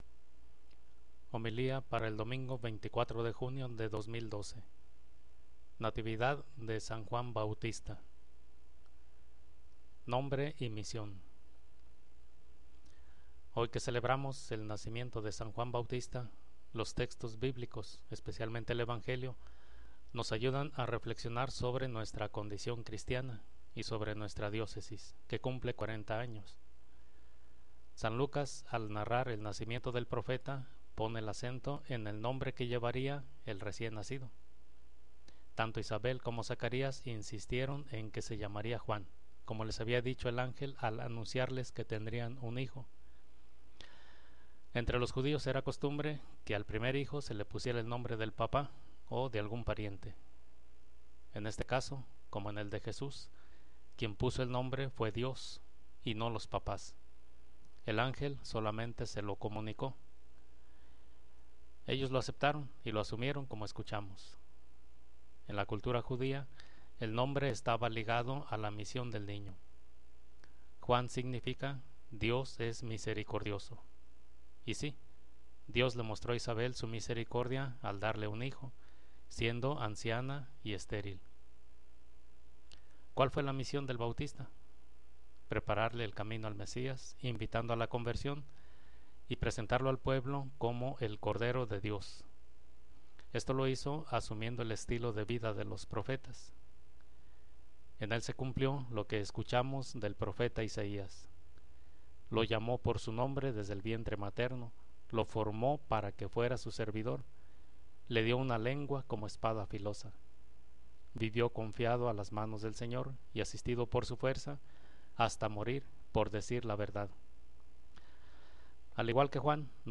Homilía de la Fiesta de la Natividad de San Juan Bautista 2012